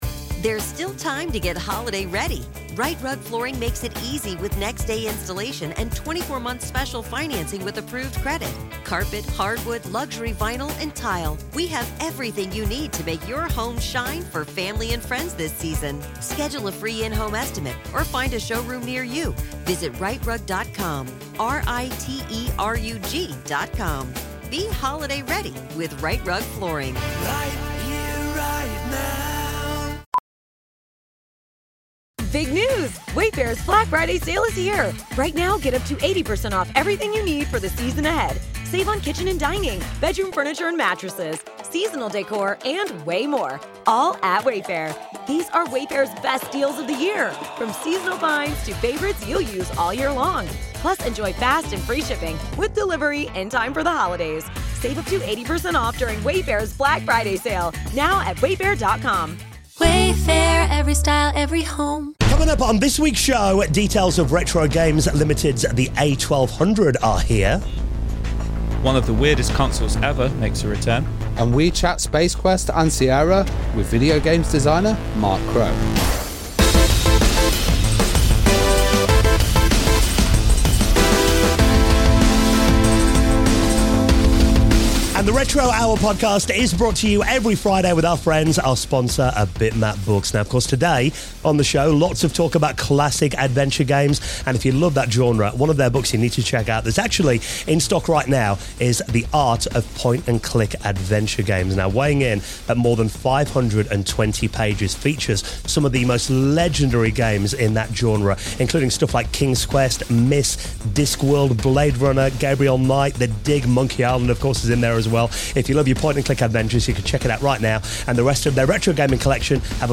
This week we're joined by Sierra legend Mark Crowe, co-creator of the Space Quest series and one half of the iconic Two Guys from Andromeda. Mark reveals how he went from never touching a computer to helping define the golden age of adventure gaming, working alongside Roberta Williams, Disney, and a hapless space janitor named Roger Wilco. Plus, we hear about the long-awaited reunion that brought Space Venture to life.
58:10 - Mark Crowe Interview